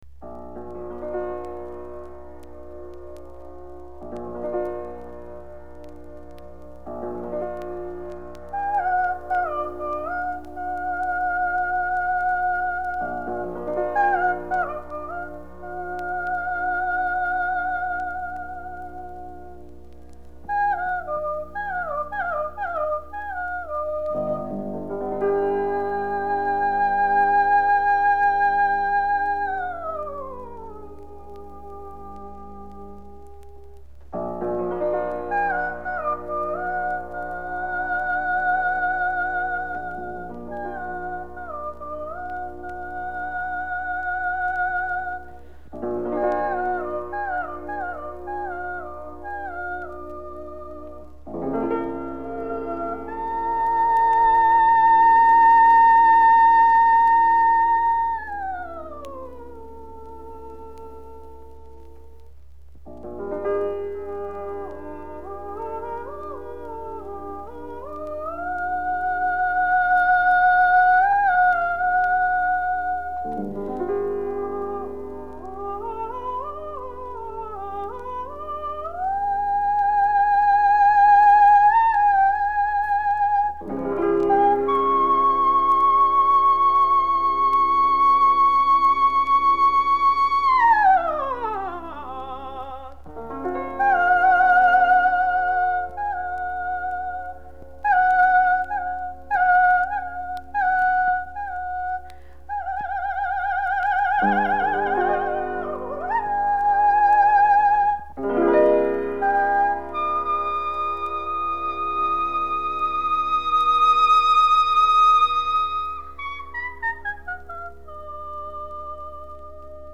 вокализ
фортепьяно